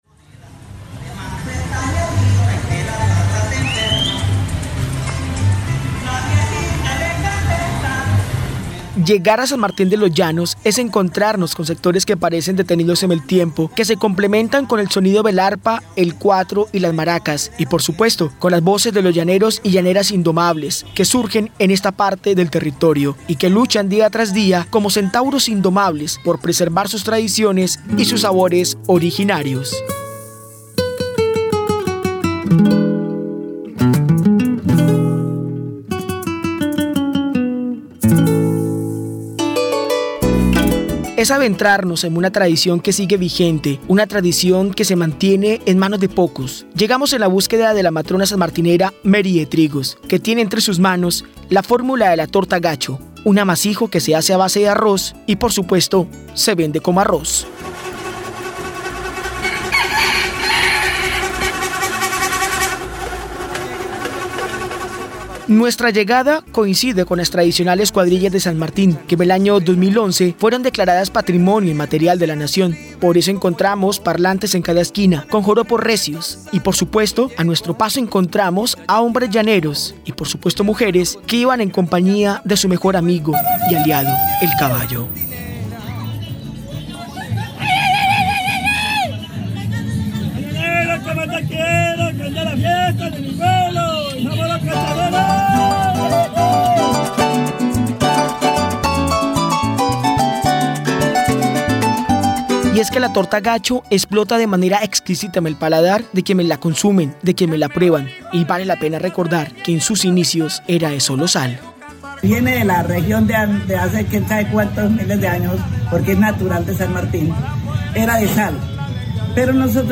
En medio de arpas y maracas aparece la receta de uno de los amasijos más representativos de San Martín de los Llanos: la torta gacho, una deliciosa preparación de cuajada y arroz, cuya fórmula ha trascendido de generación en generación.